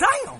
kits/Southside/Vox/OJ Damn.wav at main